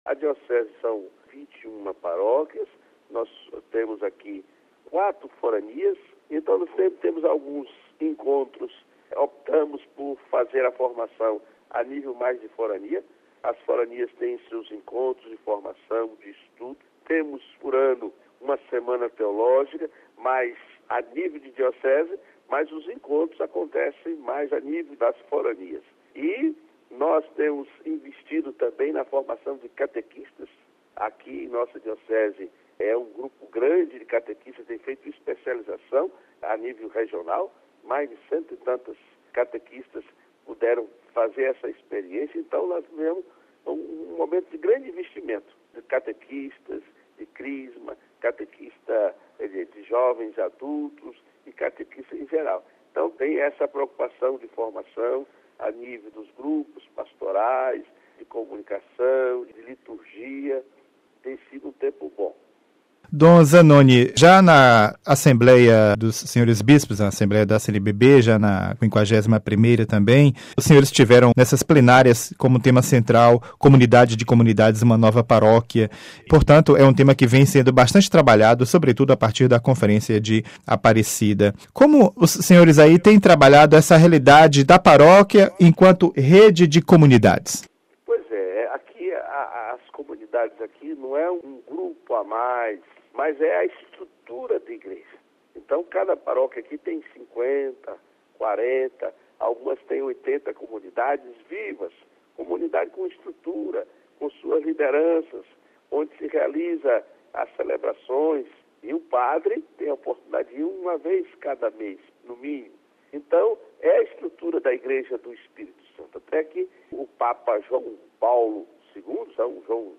Cidade do Vaticano (RV) - Amigo ouvinte, a edição de hoje do quadro "O Brasil na Missão Continental" continua com a participação do bispo da Diocese de São Mateus – ES, Dom Zanoni Demettino Castro, com cuja preciosa contribuição temos contado nestes dias.